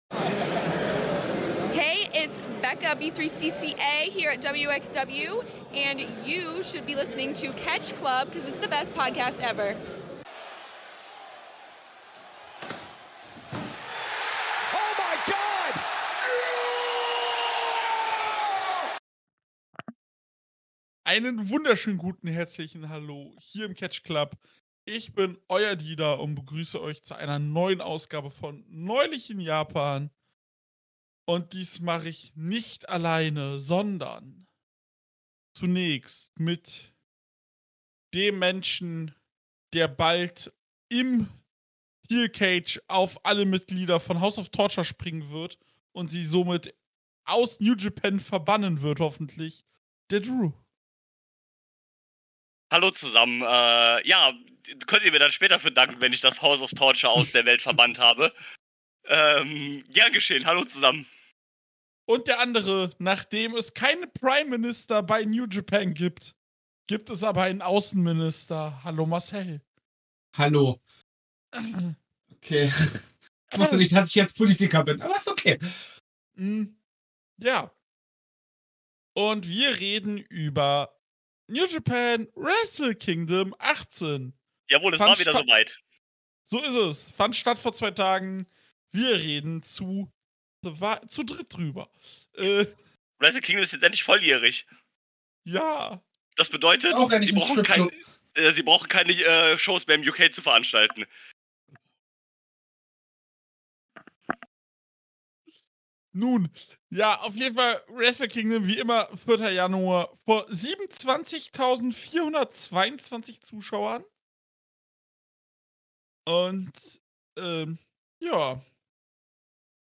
Es war mal wieder der 4.1 und das heißt Wrestle Kingdom. Zu Dritt sprechen wir über diese Show und greifen alles auf, was uns gefiel und nicht gefiel.